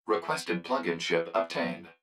042_Chip_Obtained.wav